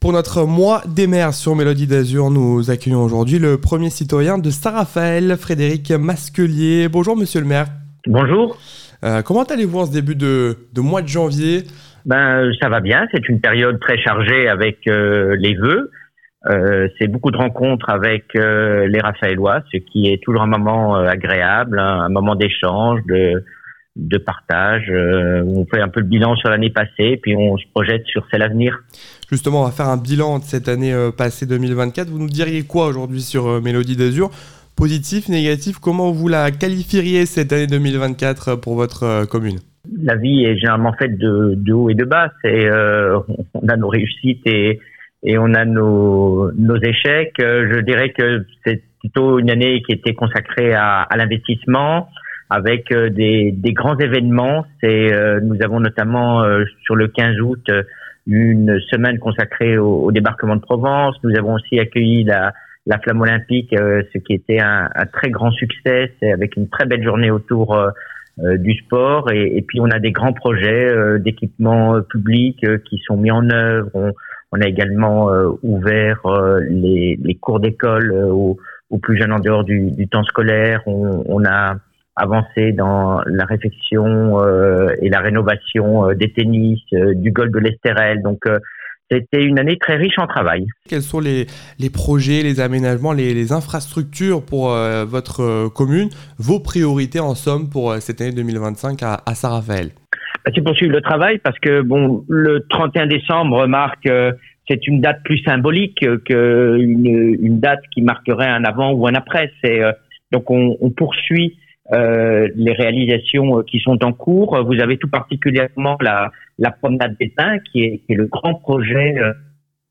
Interview des Maires - Épisode 2 : Saint-Raphaël avec Frédéric Masquelier
interview-des-maires-episode-2-saint-raphael-avec-frederic-masquelier.mp3